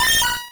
Cri de Miaouss dans Pokémon Rouge et Bleu.